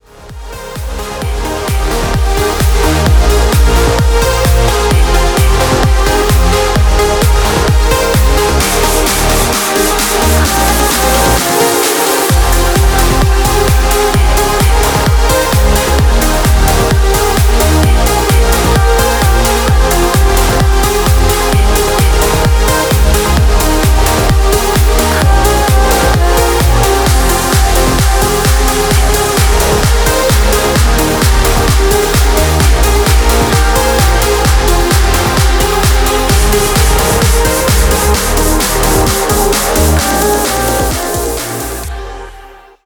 громкие
мощные
progressive trance
vocal trance